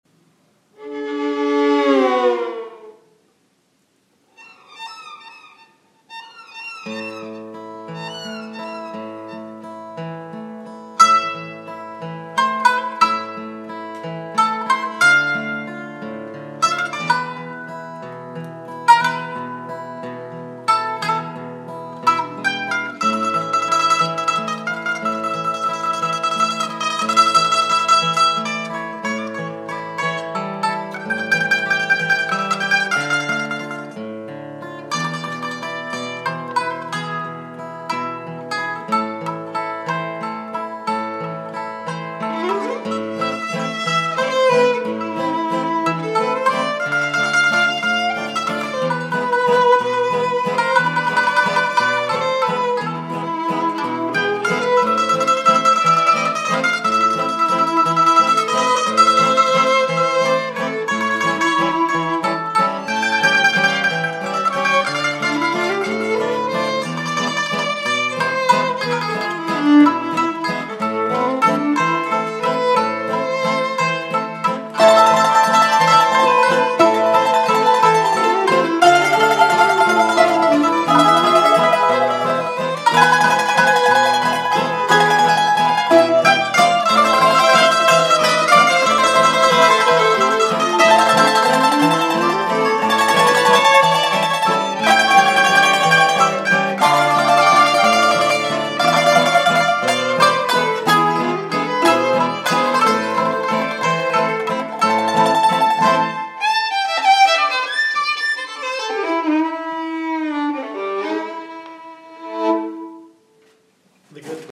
Ο Γιώργος Νινιός ερμηνεύει και ντύνει με ήχους και πρωτότυπη δική του μουσική και στίχους επιλογές από το ωριμότερο και ποιητικότερο έργο του Μ. Καραγάτση.
Ερμηνεία: Γιώργος Νινιός
Μουσική – Στίχοι: Γιώργος Νινιός
τζουράς, μαντολίνο
βιολί
μπουζούκι
κιθάρα
τζουράς, κλαρίνο